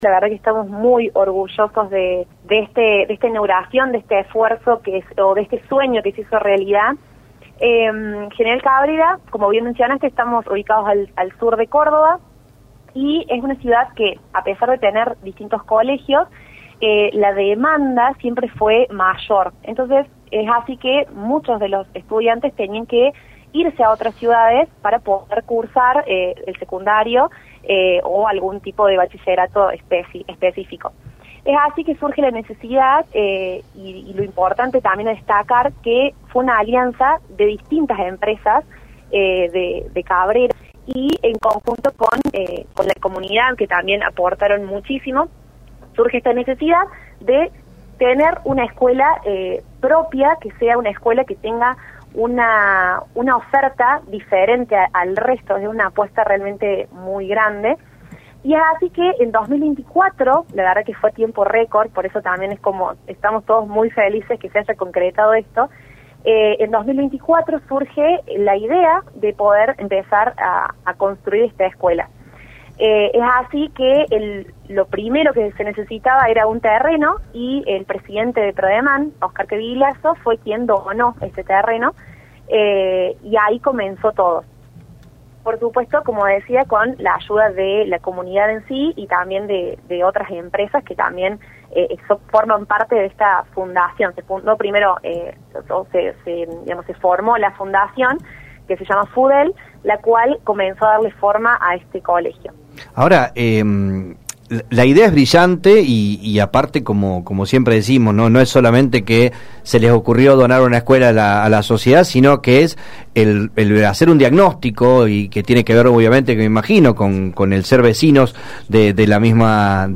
habló en el programa Media Mañana